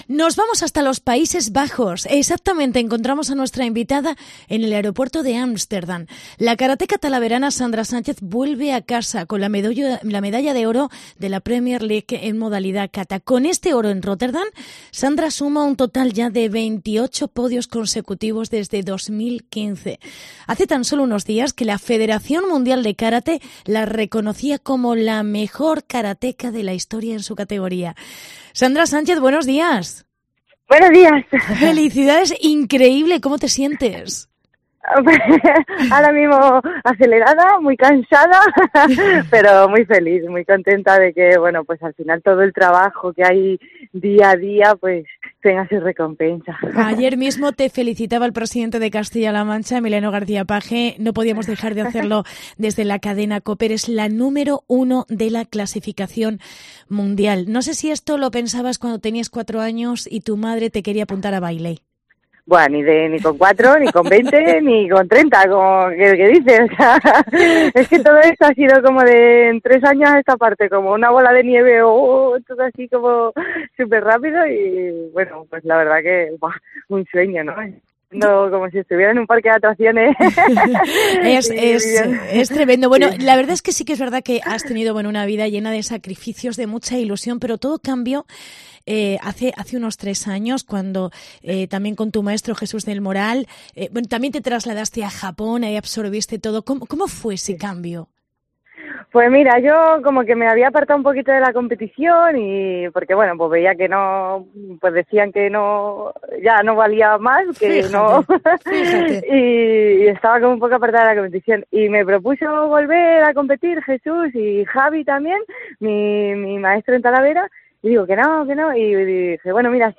Entrevista con Sandra Sánchez